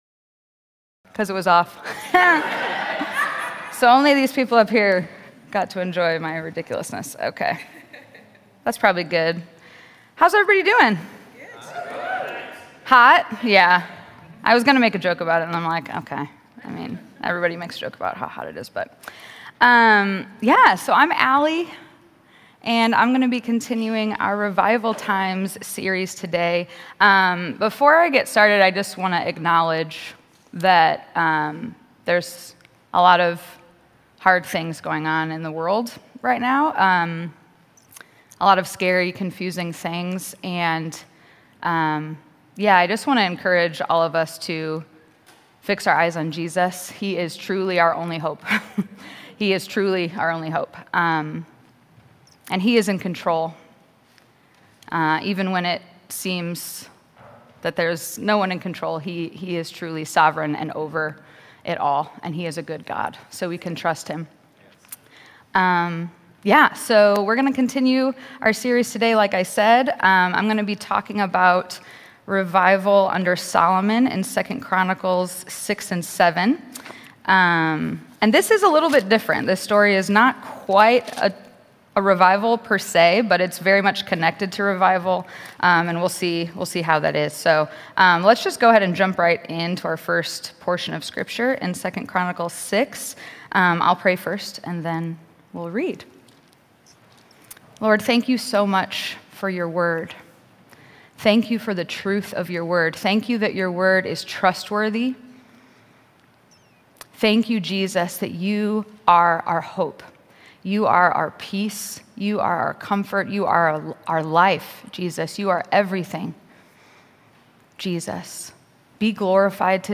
A message from the series "Revival Times."